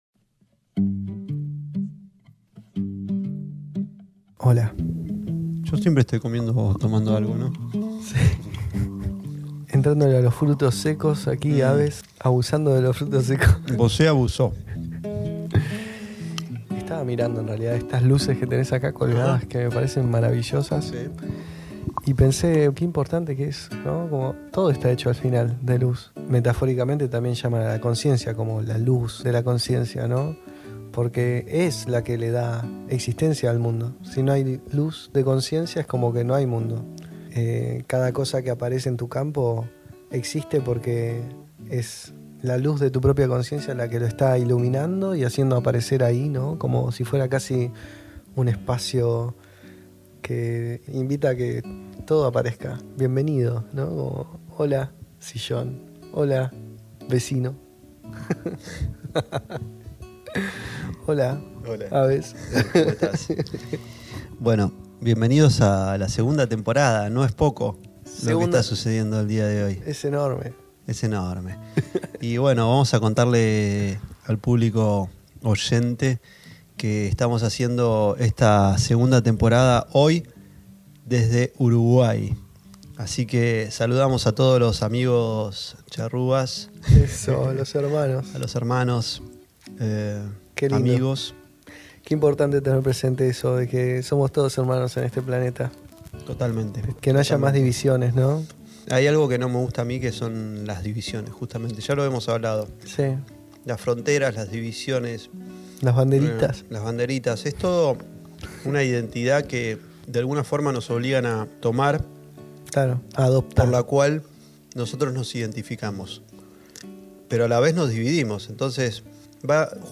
Nueva temporada, este capitulo desde Uruguay. Surge una charla amena sobre el poder personal de cada individuo y sobre las leyes universales.